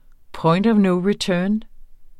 Udtale [ ˈpʌjnd ʌf ˈnɔw ɹiˈtœːn ]